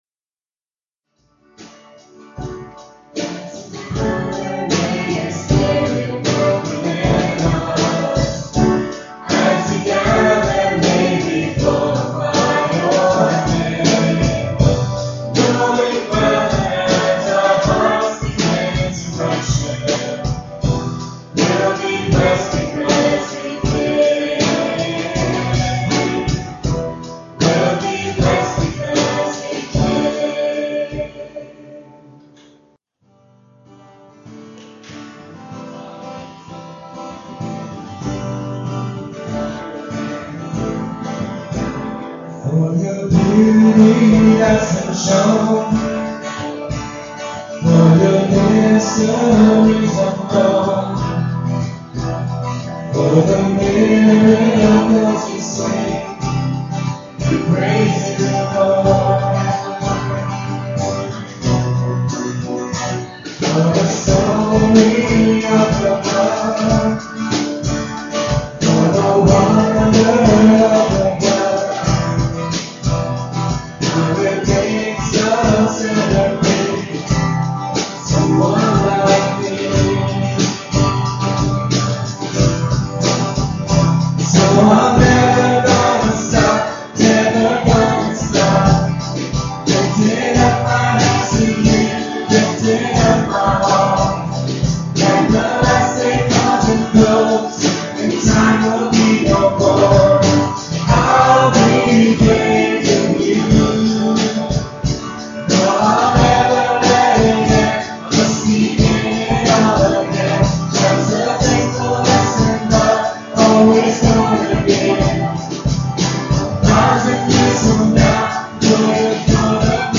at Ewa Beach Baptist Church
guitar and vocals
electric bass, vocals
drums.